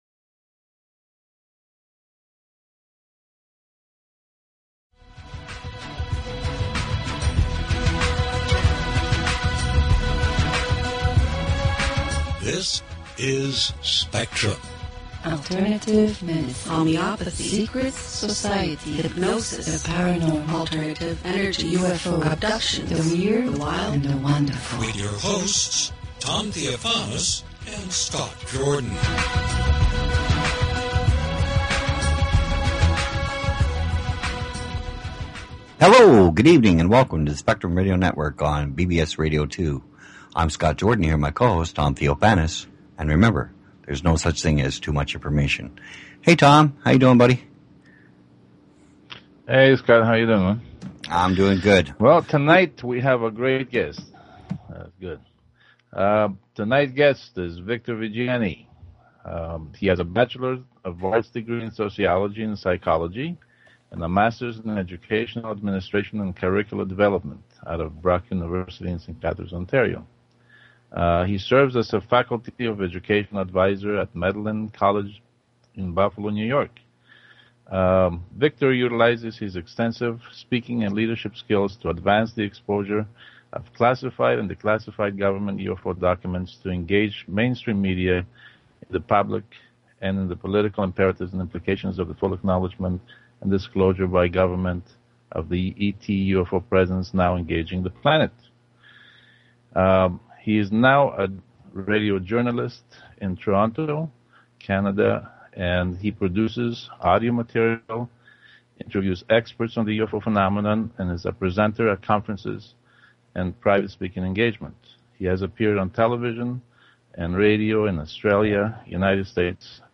Talk Show Episode, Audio Podcast, Spectrum_Radio_Network and Courtesy of BBS Radio on , show guests , about , categorized as